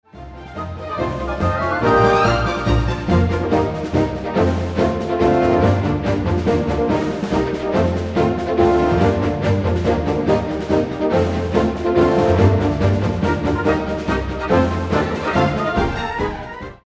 вот своеобразный пример на работу RR, только не в одном инструменте, а в контексте 4-х валторн из берл.брасов. Трижды повторяемую фразу (фактически копи-паст) , без изменения какой-либо динамики можно не просто скопировать, но поменять нотки у валторн - верхний тон в след. фразе от первой валторны отдать второй и т.д. Потусовать, короче.) В результате ни одна из этих трёх фраз не повторится зеркально..